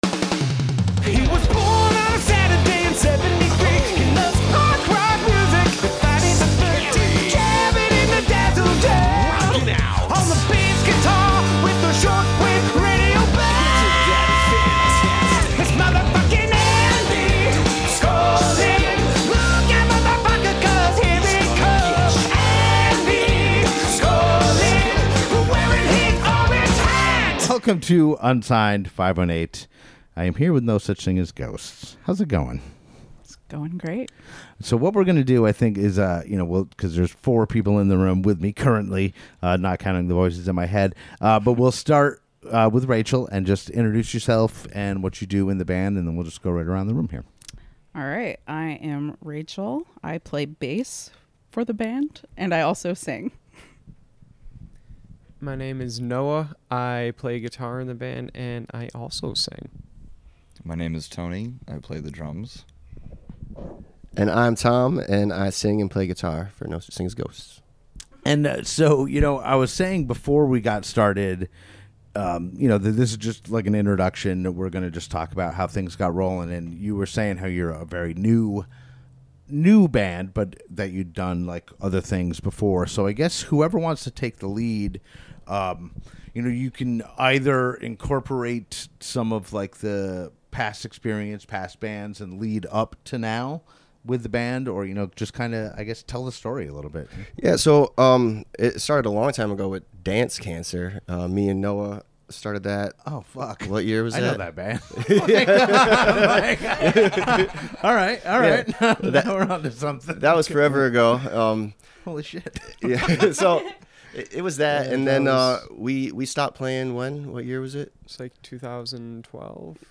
So, check out episode 128, which includes 2 live in-studio performances from No Such Thing As Ghosts.